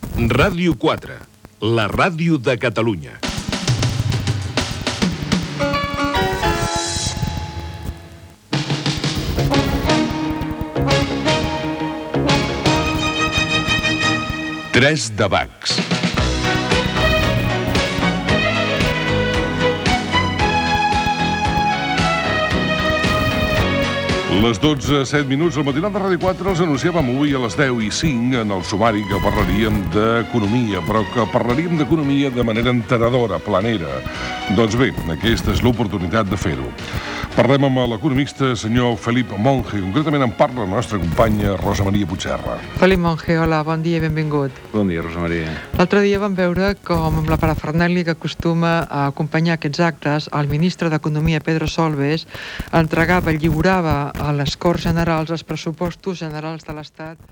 Indicatiu de l'emissora i inici del programa.